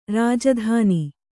♪ rāja dhāni